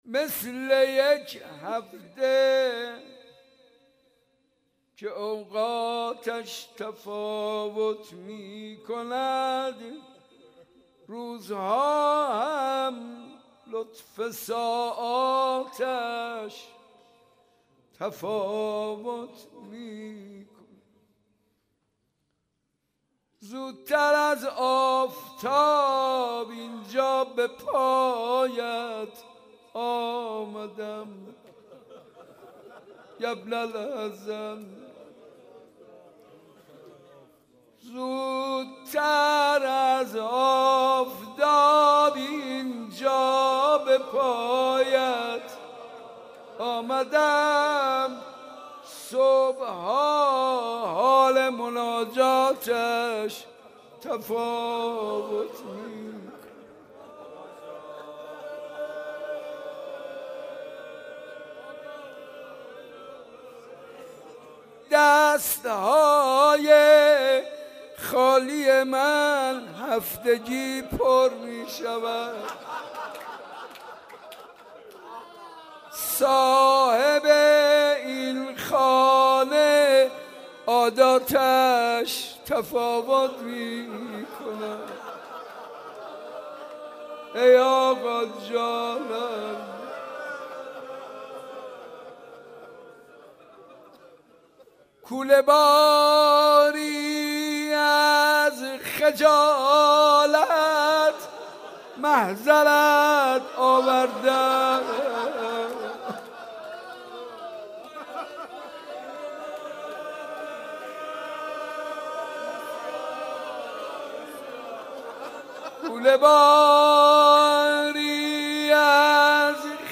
مثل یک هفته که اوقاتش تفاوت می کند | مناجات با امام زمان
زیارت عاشورای صنف لباس فروش ها